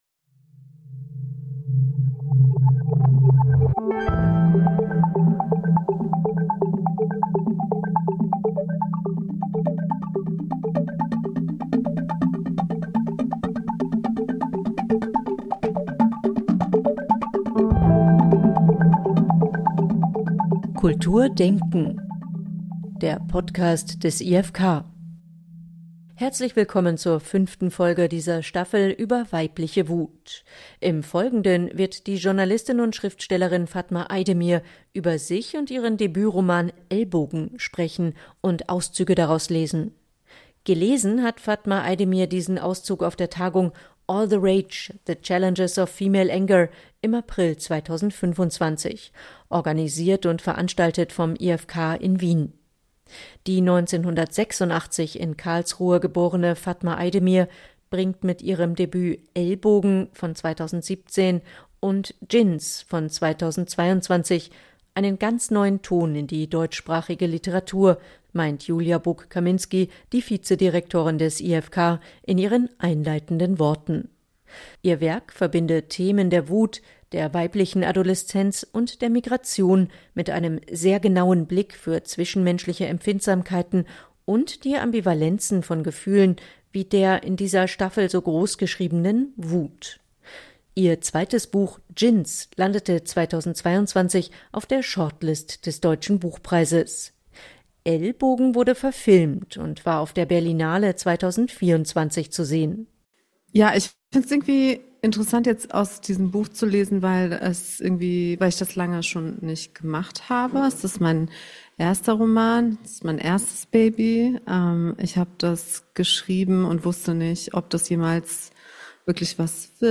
Beschreibung vor 2 Monaten In dieser Folge geht es um den Roman »Ellbogen« der Autorin und Journalistin Fatma Aydemir. 2017 bei Hanser erschienen, erhielt Aydemir für ihr Debüt den Klaus-Michael-Kühne-Preis und den Franz-Hessel-Preis, 2024 wurde der Roman verfilmt. Aydemir liest einen Ausschnitt aus »Ellbogen« vor, gibt Einblick in ihre eigene Biographie und die Entstehungsgeschichte ihres Werkes. Der Roman erzählt die Geschichte der 17-jährigen Hazal, die in Berlin-Wedding aufwächst, von der Mehrheitsgesellschaft marginalisiert wird und nach einem gewalttätigen Vorfall nach Istanbul flieht.